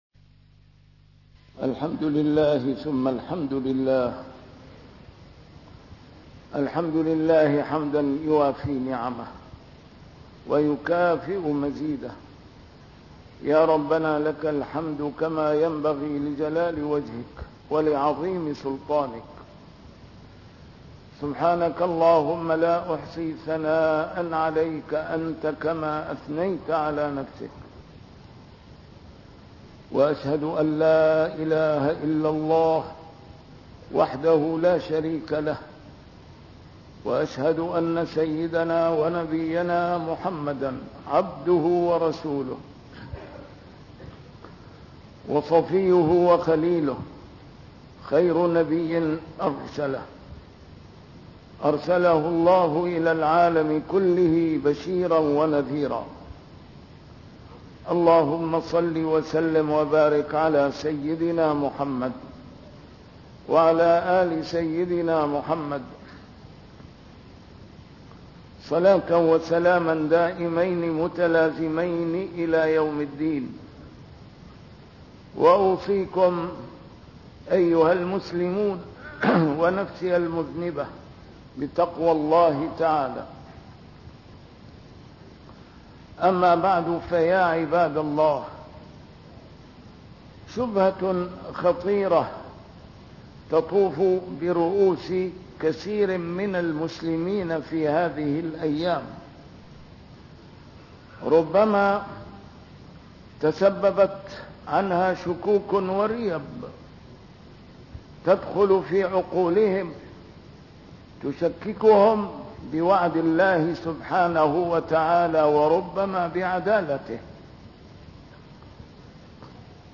A MARTYR SCHOLAR: IMAM MUHAMMAD SAEED RAMADAN AL-BOUTI - الخطب - متى نصر الله؟